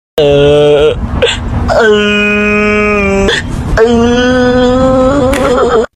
失望哭泣